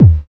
EQ KICK 6 2.wav